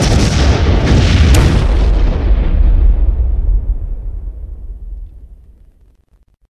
explode.ogg